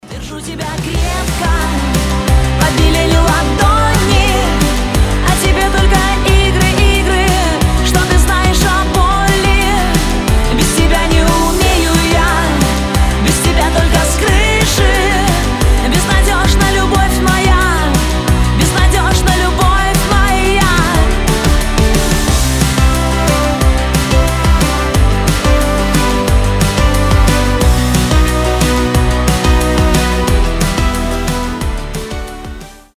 • Качество: 320, Stereo
женский голос
Pop Rock
легкий рок